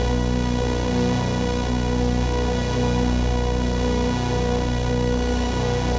Index of /musicradar/dystopian-drone-samples/Non Tempo Loops
DD_LoopDrone5-B.wav